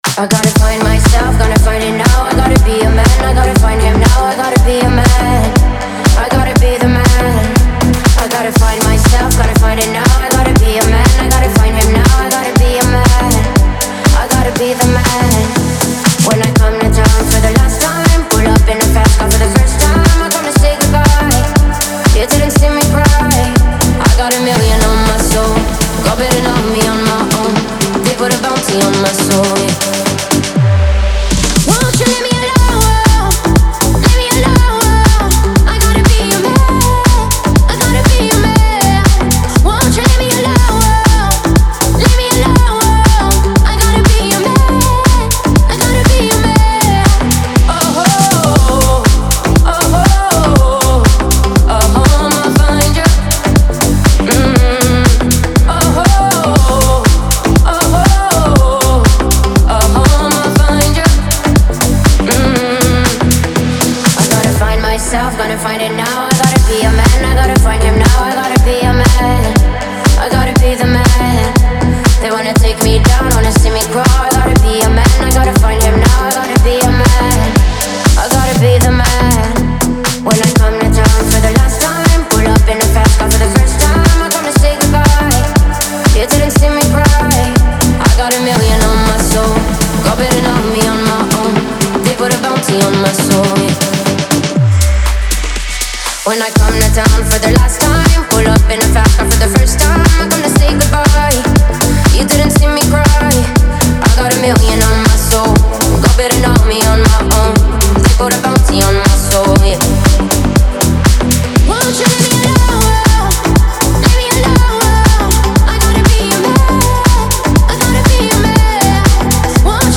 это яркая и энергичная трек в жанре EDM